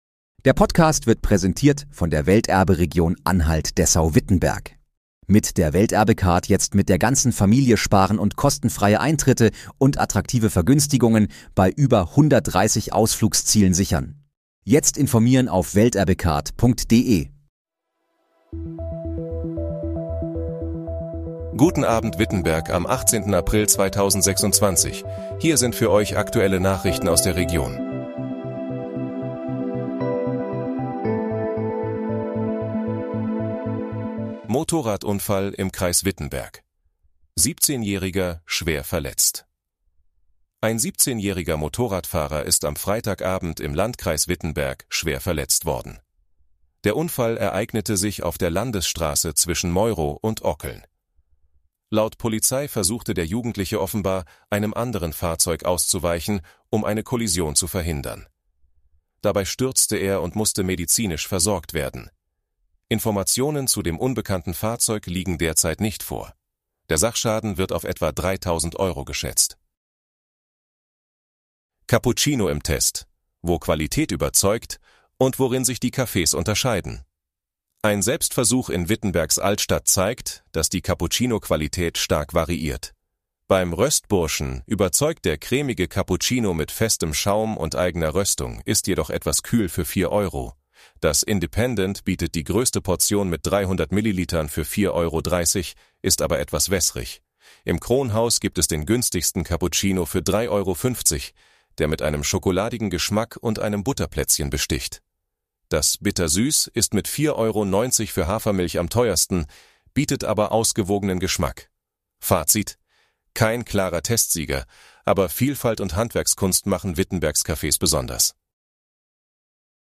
Guten Abend, Wittenberg: Aktuelle Nachrichten vom 18.04.2026, erstellt mit KI-Unterstützung